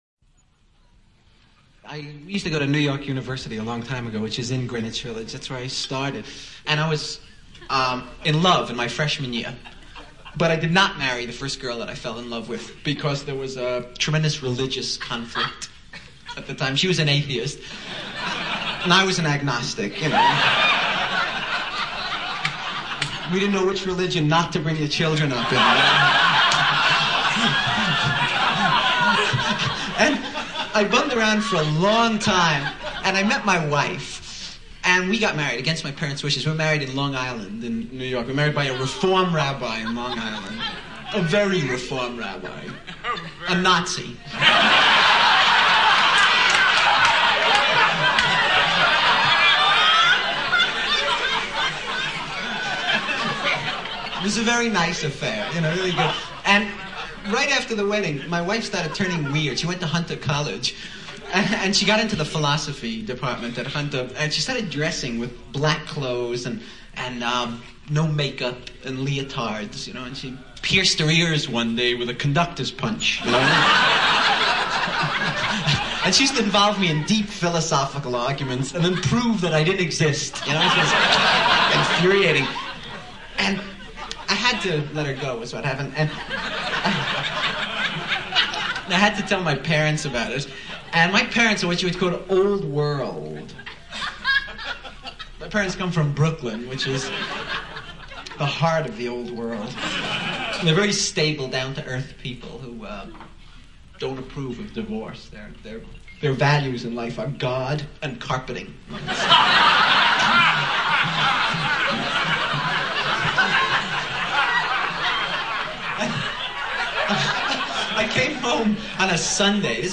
伍迪单口相声精选 第20期:纽约大学N.Y.U. 听力文件下载—在线英语听力室